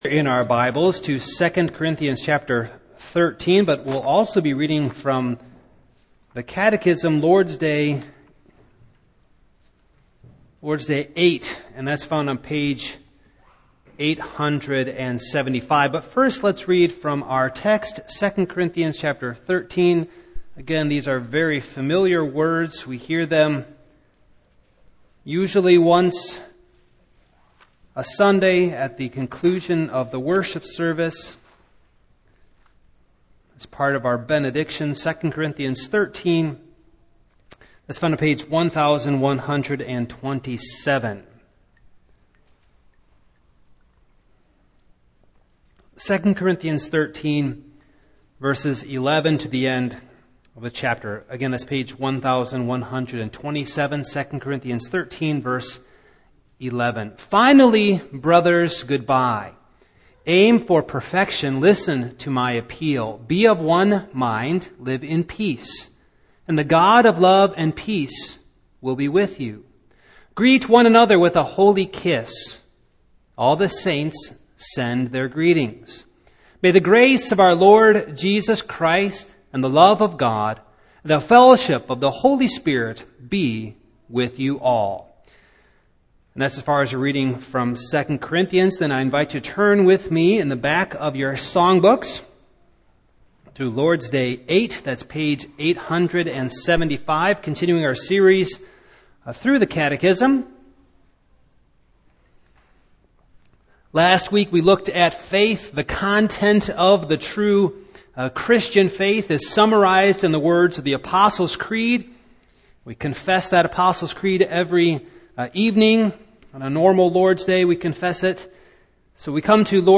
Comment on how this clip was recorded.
Passage: 2 Cor. 13:11-14 Service Type: Evening